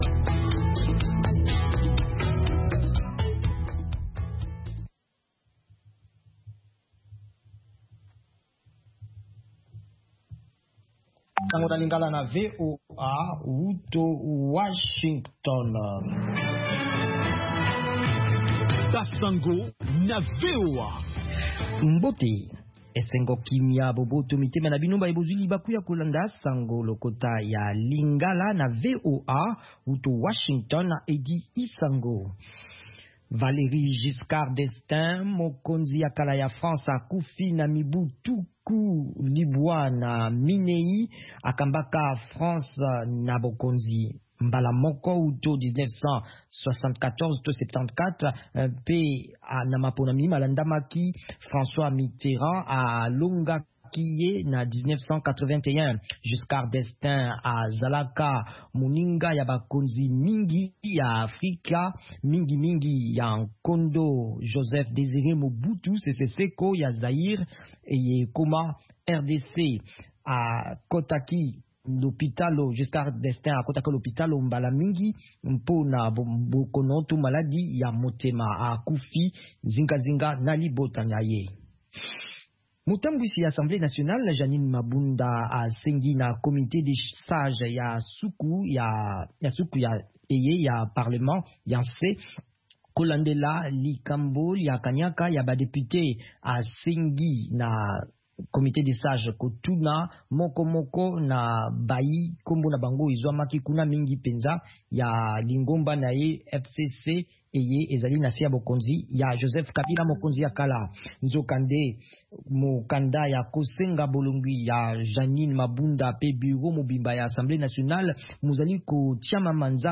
Basango na VOA Lingala
Radio